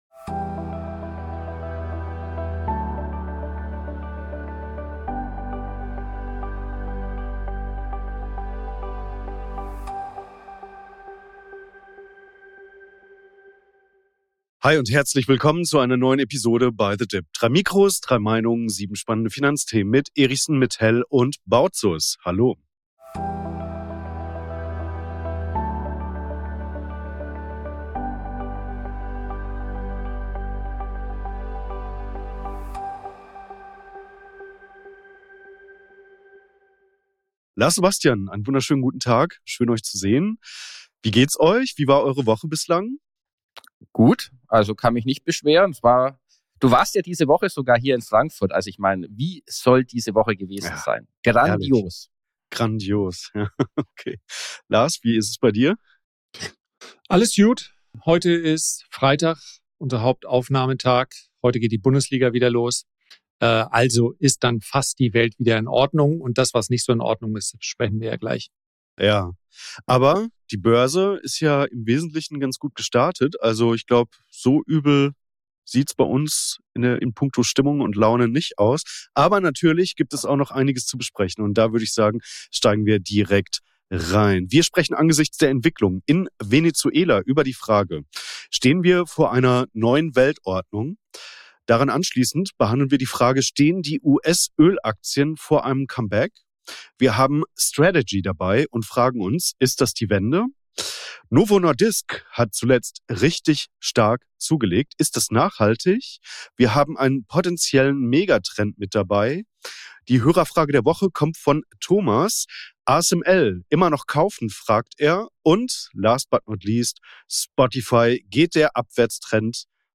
Auch diese Woche begrüßen wir euch unter dem Motto „3 Mikrofone, 3 Meinungen“ zu den folgenden Themen in dieser Ausgabe: